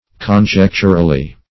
Conjecturally \Con*jec`tur*al*ly\, adv.